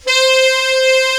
Index of /90_sSampleCDs/Giga Samples Collection/Sax/HARD + SOFT
TENOR SOFT C.wav